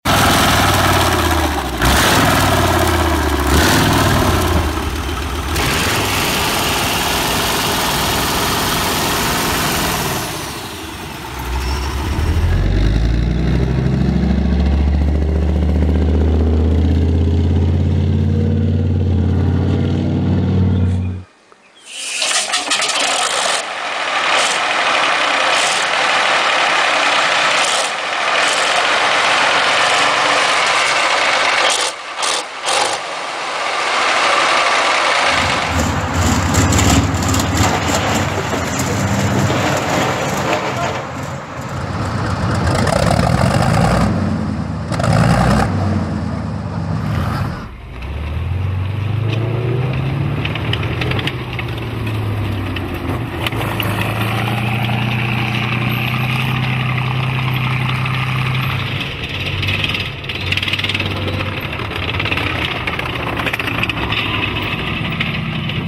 Big Tank Engines Starting Up sound effects free download